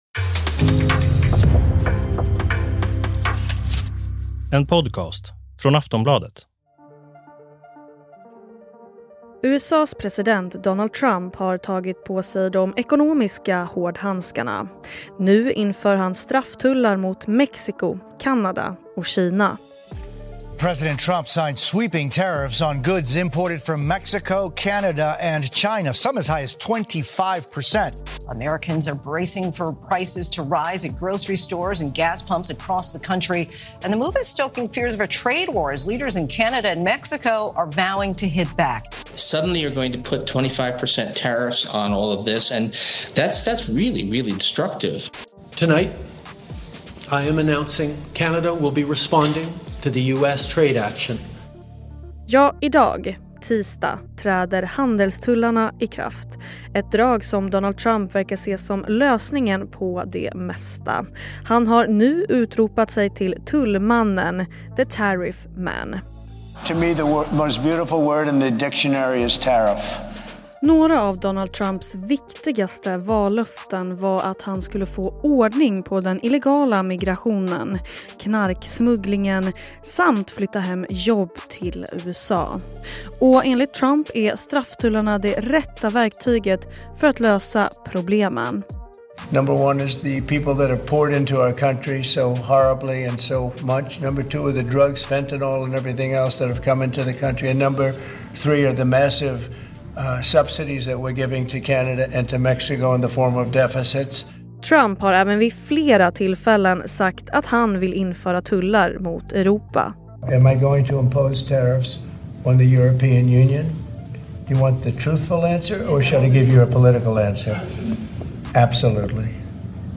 Klipp i avsnittet: NBC News, ABC News, Bloomberg.